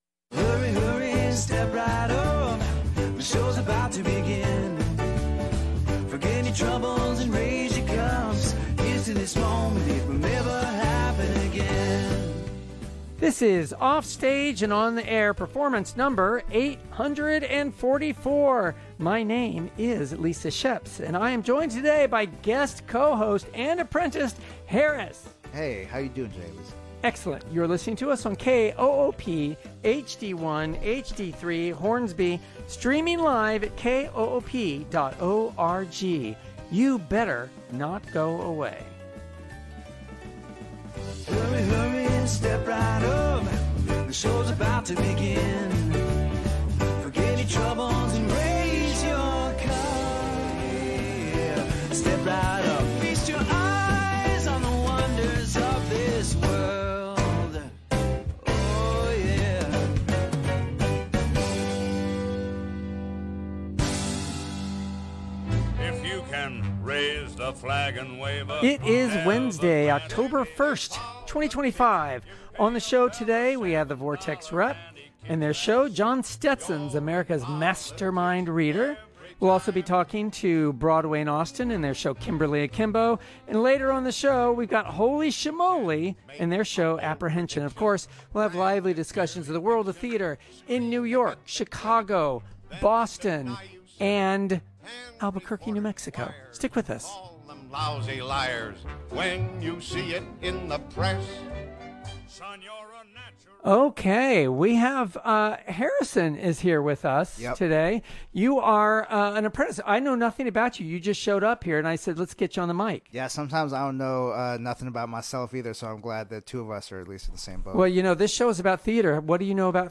A lively and fun conversation about Theatre around the country and the local Austin Theatre Sce ne